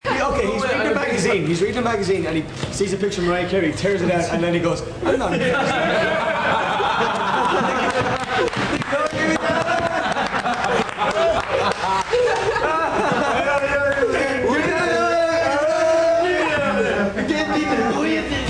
Parts of the interview (the blue ones) are also recorded in MP3 format!!!